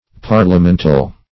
Parliamental \Par`lia*men"tal\